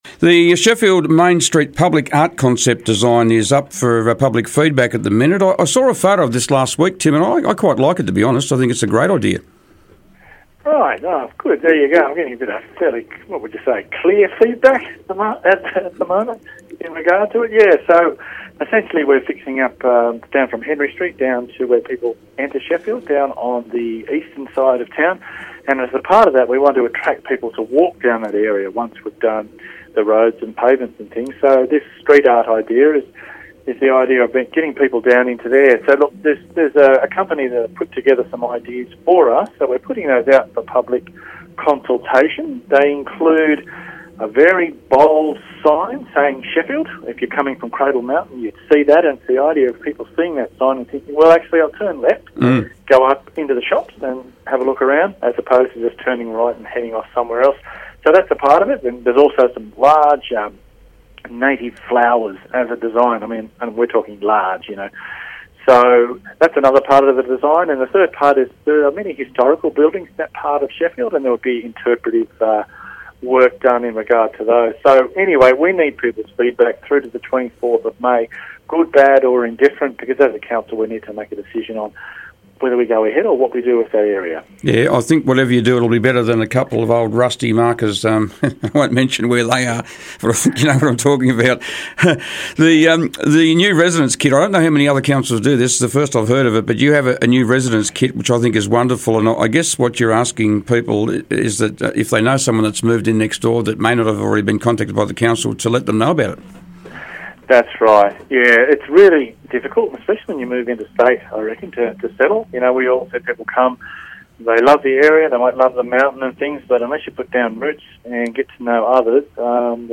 Kentish Mayor Tim Wilson was today's Mayor on the Air.